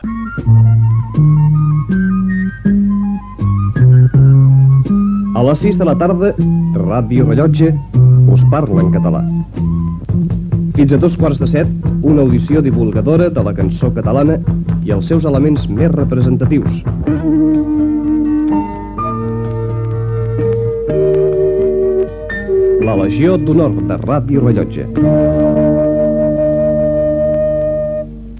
Presentació del programa
Musical